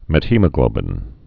(mĕt-hēmə-glōbĭn)